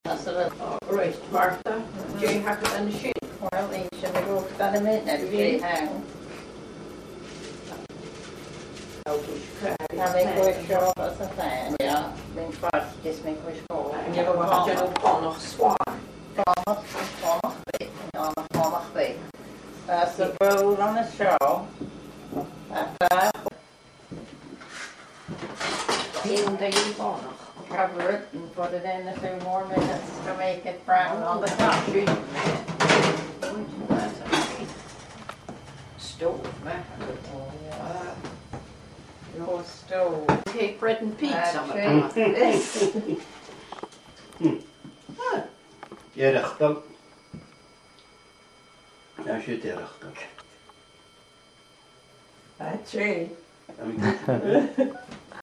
Fear-agallaimh